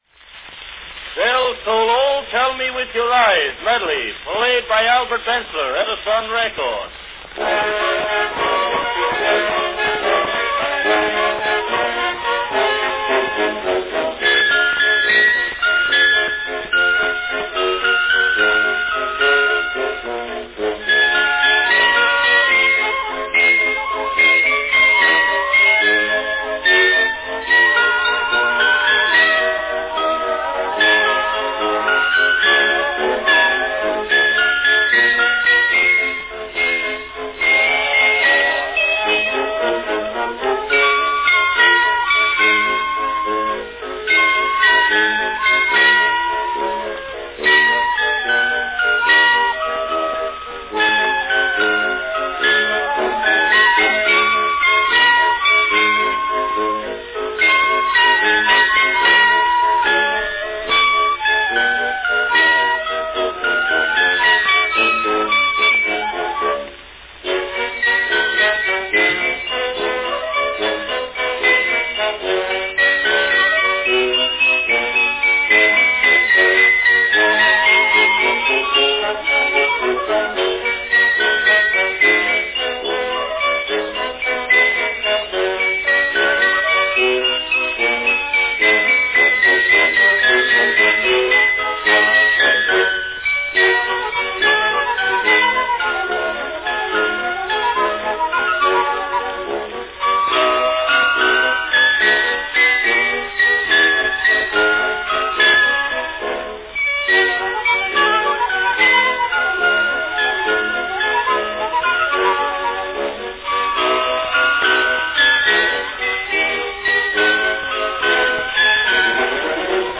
A sprightly number featuring bells and flute from 1905
Category Bells
is a bell solo with orchestra accompaniment.
These popular songs are given with elaborate orchestration.